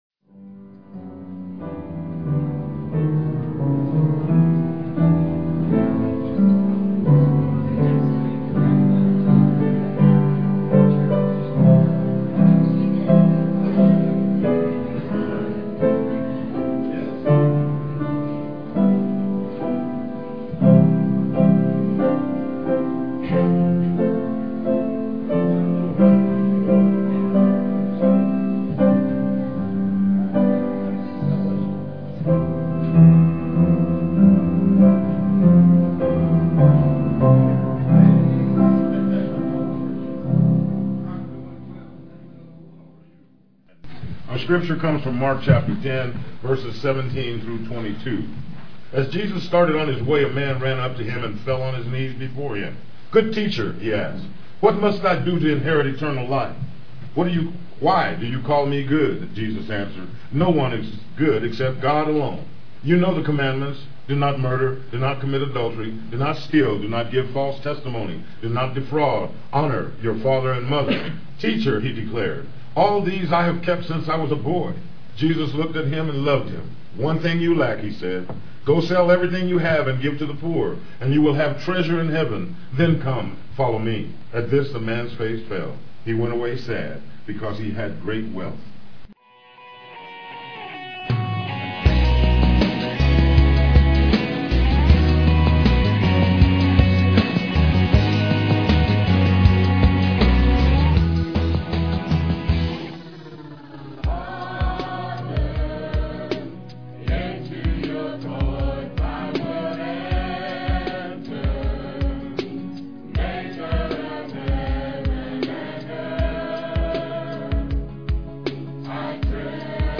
Scripture, Mark 10:17-22, read